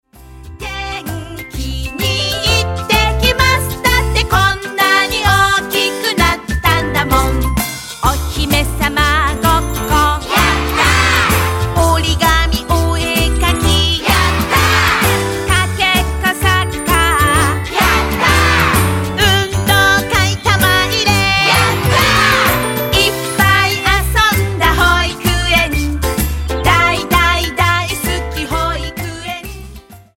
【みんなでうたおう！元気になれるうた】